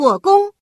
female